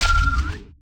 UIClick_Mallet Low Pitch Heavy 03.wav